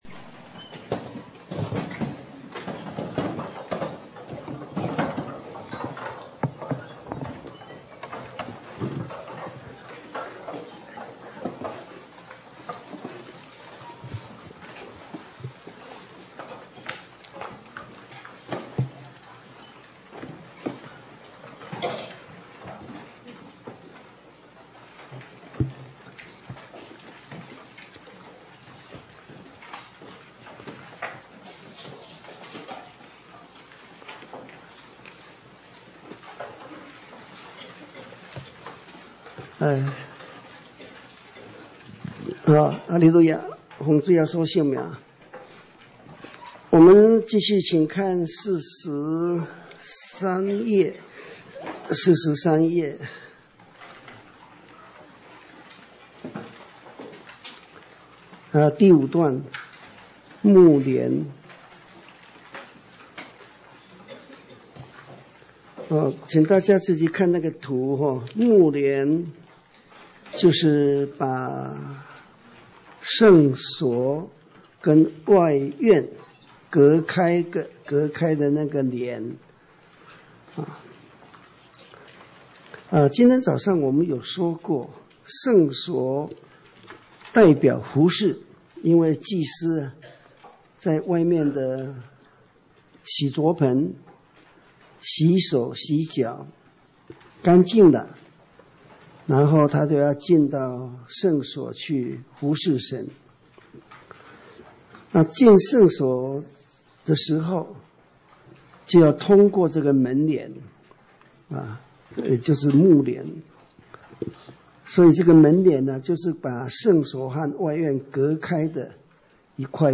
講習會